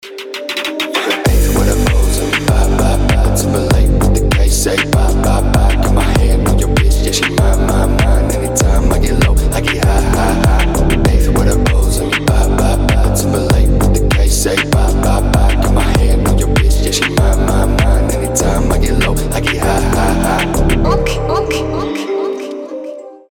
• Качество: 320, Stereo
атмосферные
качающие
G-House
abstract hip-hop